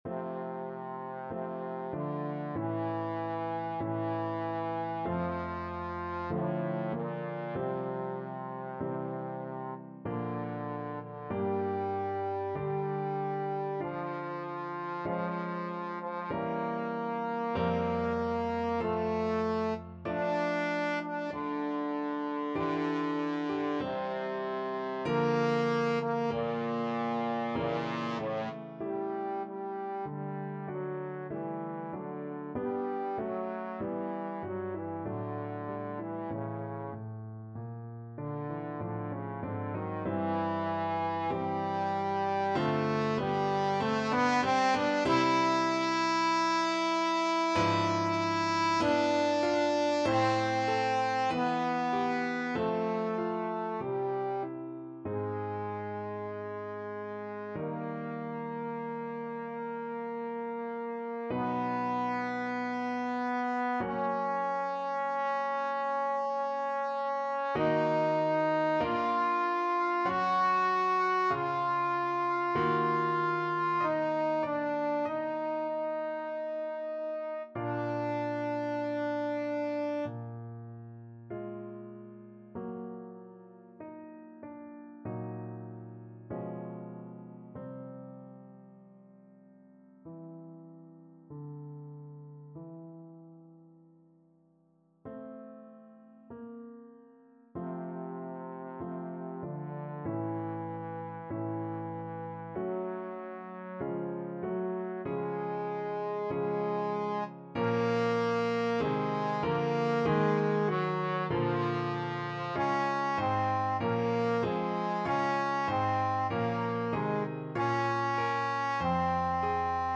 Trombone version
~ = 96 Alla breve. Weihevoll.
Classical (View more Classical Trombone Music)